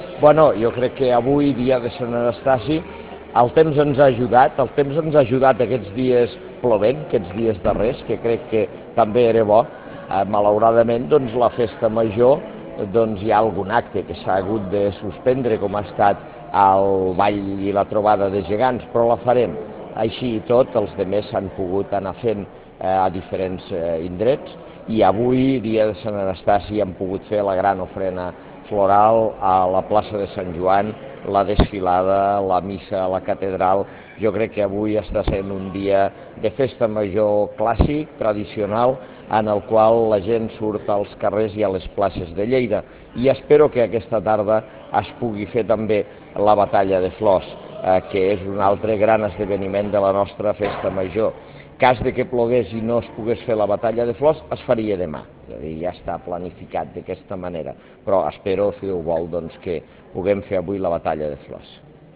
ARXIU DE VEU on Ros destaca la participació popular
arxiu-de-veu-on-ros-destaca-la-participacio-popular